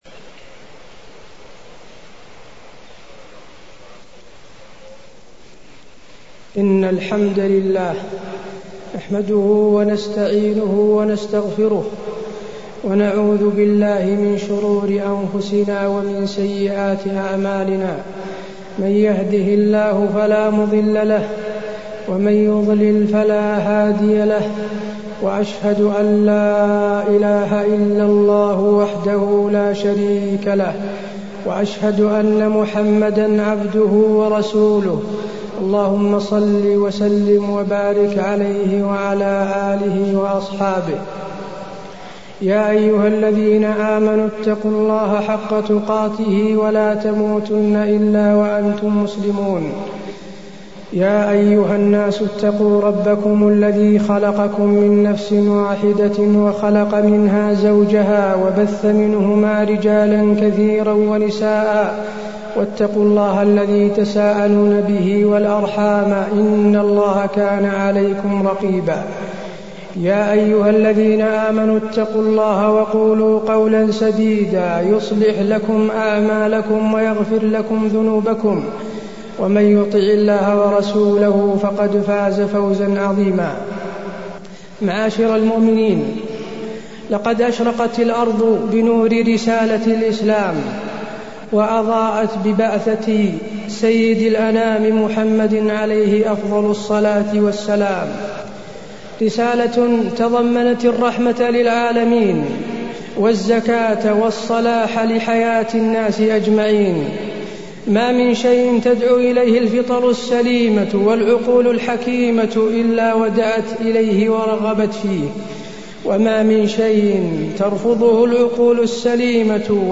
تاريخ النشر ١٣ رجب ١٤٢٣ هـ المكان: المسجد النبوي الشيخ: فضيلة الشيخ د. حسين بن عبدالعزيز آل الشيخ فضيلة الشيخ د. حسين بن عبدالعزيز آل الشيخ الأخلاق الإسلامية